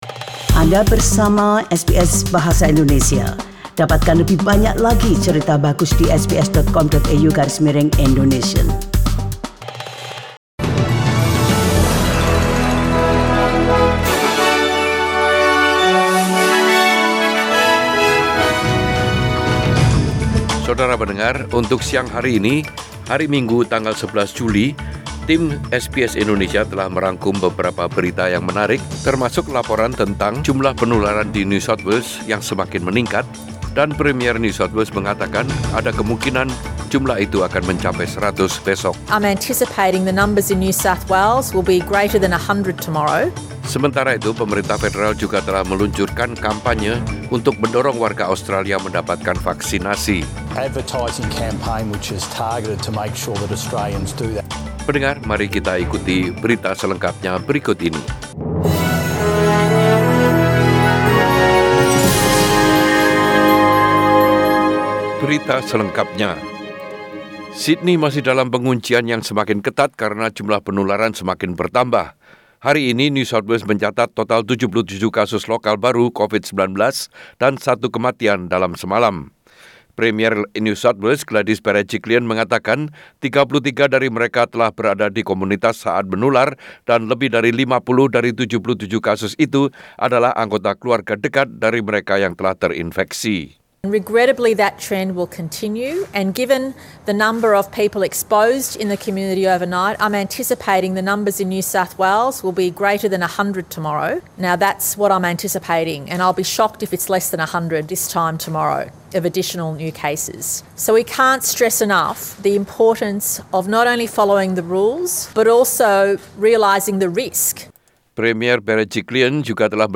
Warta Berita SBS Radio dalam Bahasa Indonesia Source: SBS